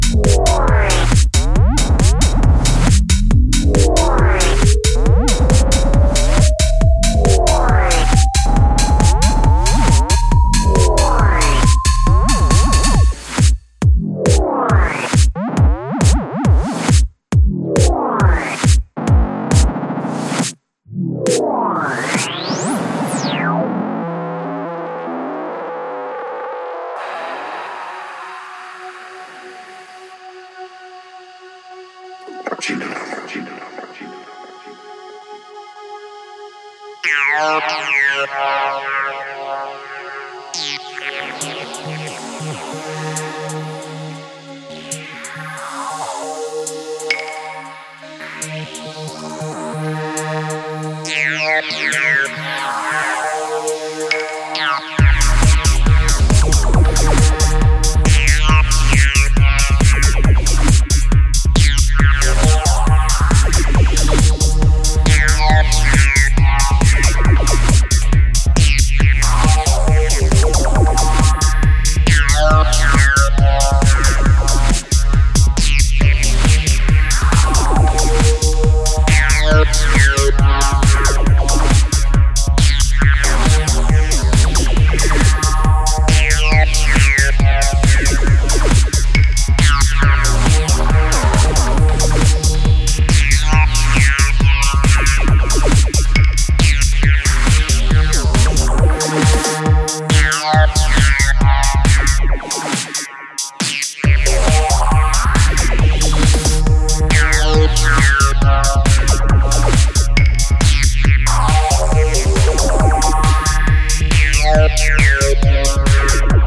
and a fresh perspective on underground dance music